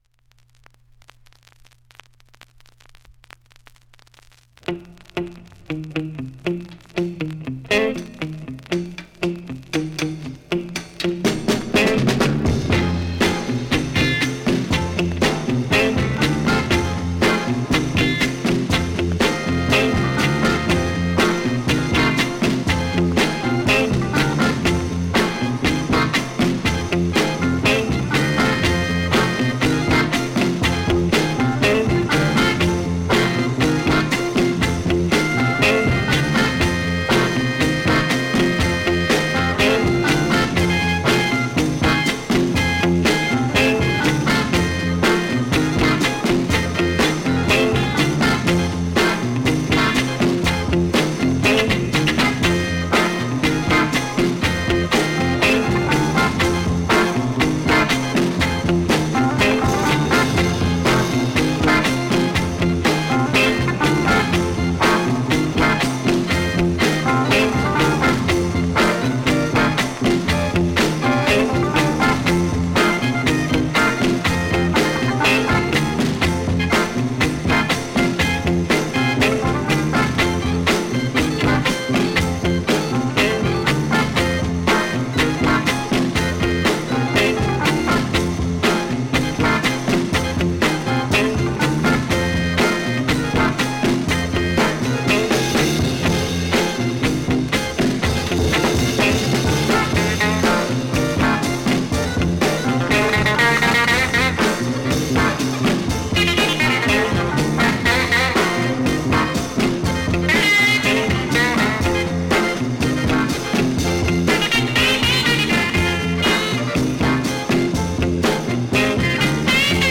◆盤質Ａ面/VG◆盤質Ｂ面/Gスレ多しですが普通に聴けます